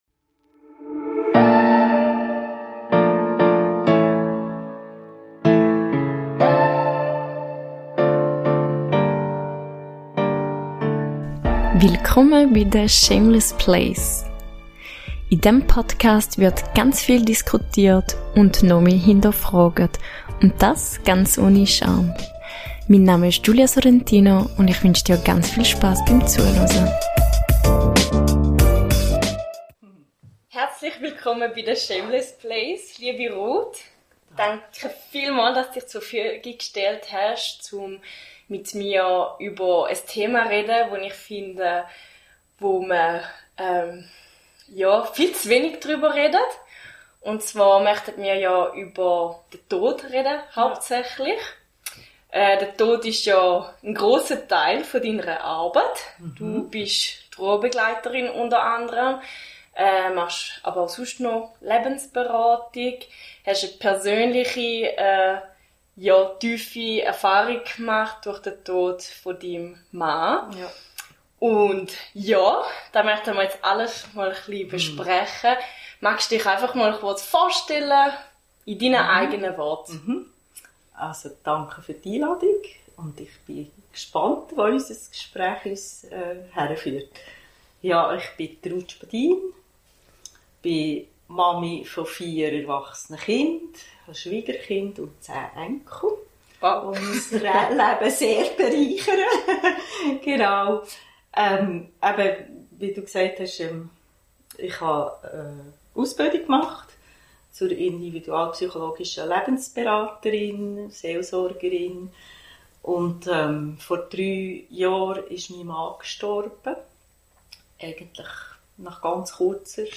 Der Tod macht unser Leben wertvoll - Interview